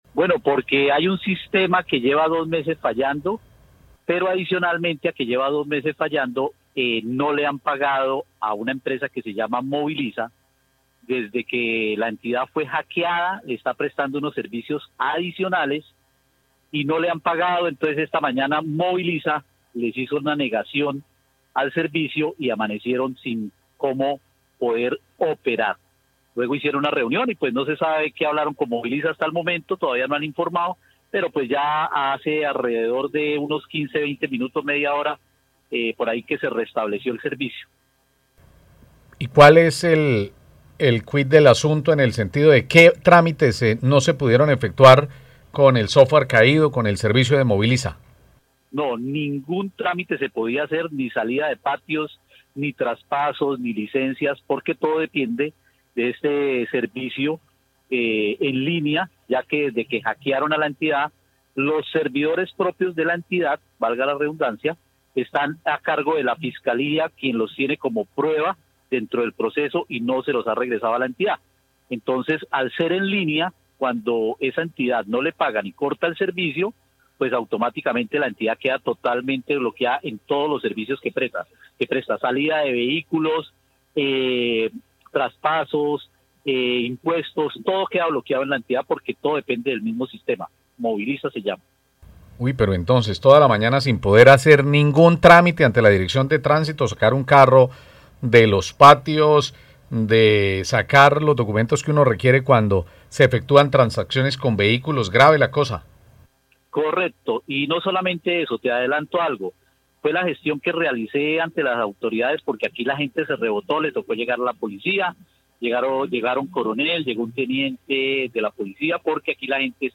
veedor comunidad